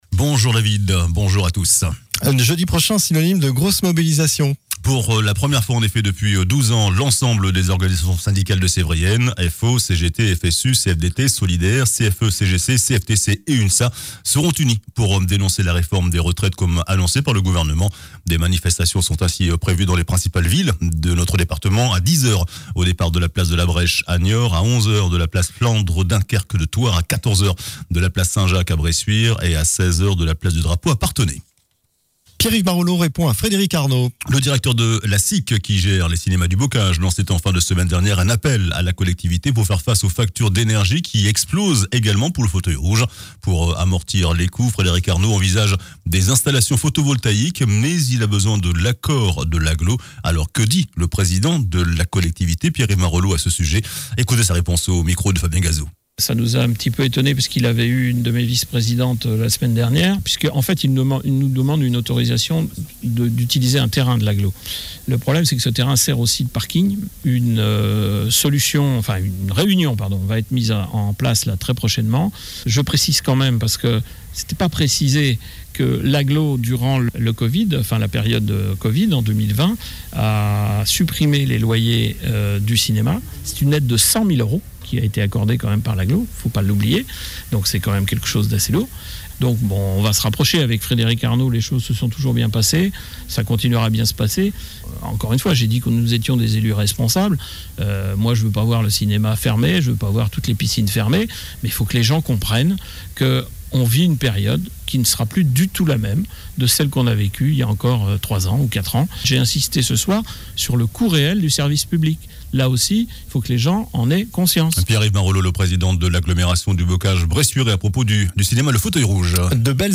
JOURNAL DU SAMEDI 14 JANVIER